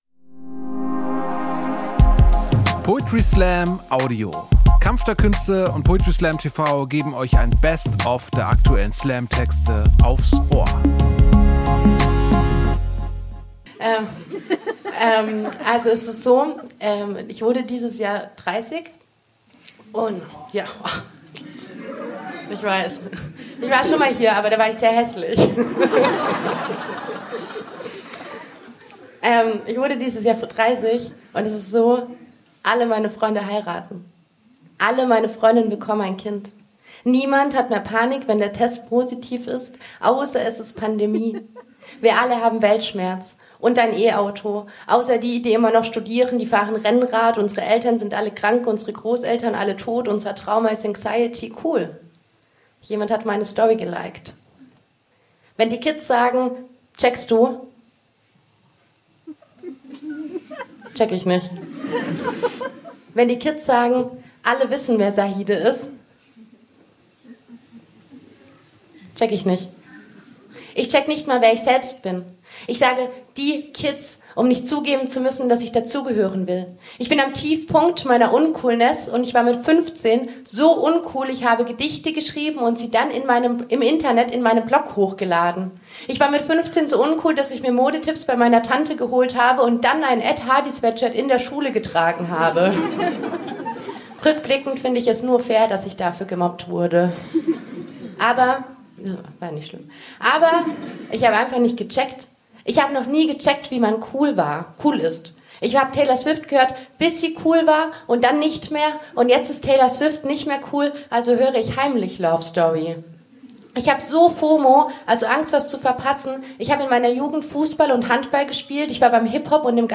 Kunst , Comedy , Gesellschaft & Kultur
Stage: Ernst Deutsch Theater, Hamburg